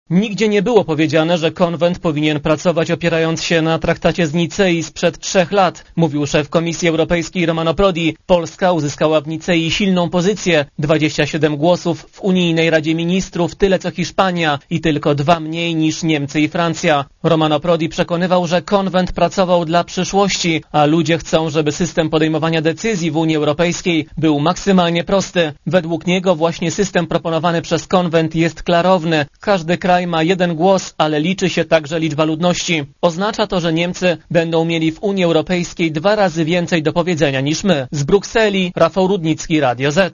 Komentarz audio (160Kb)